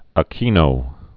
(ä-kēnō), Corazón Cojuangco 1933-2009.